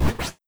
Impact5.wav